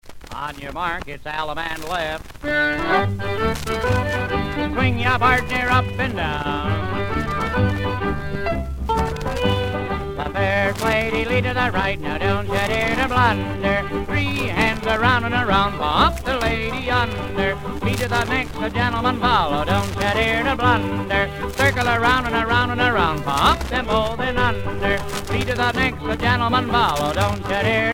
danse : square dance
Pièce musicale éditée